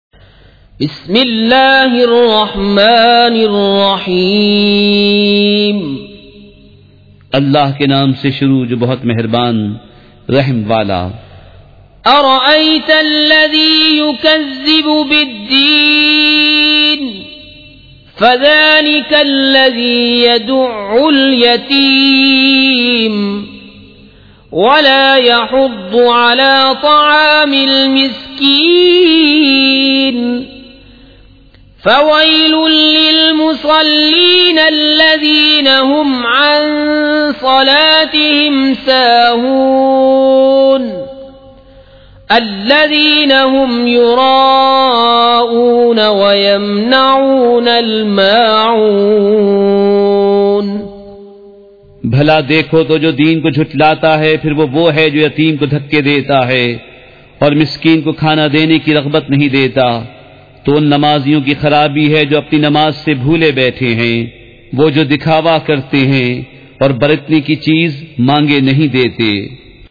سورۃ الماعون مع ترجمہ کنزالایمان ZiaeTaiba Audio میڈیا کی معلومات نام سورۃ الماعون مع ترجمہ کنزالایمان موضوع تلاوت آواز دیگر زبان عربی کل نتائج 4510 قسم آڈیو ڈاؤن لوڈ MP 3 ڈاؤن لوڈ MP 4 متعلقہ تجویزوآراء